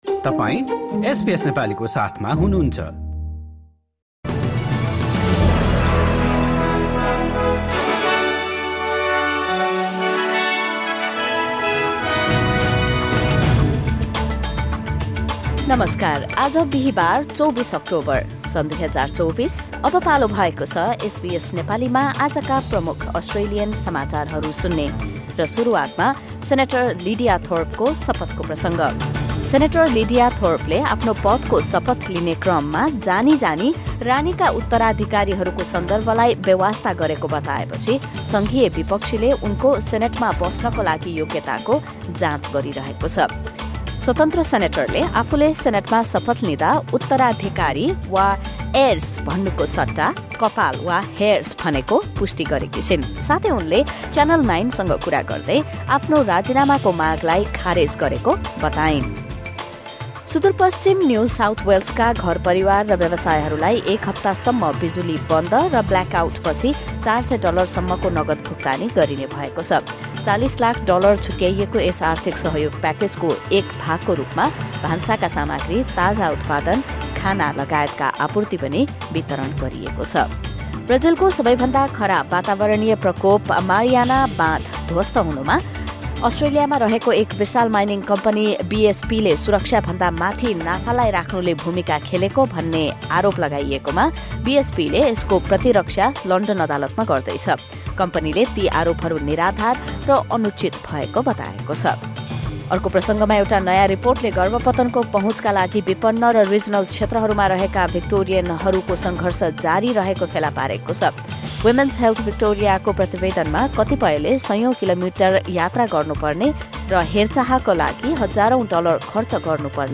SBS Nepali Australian News Headlines: Thursday, 24 October 2024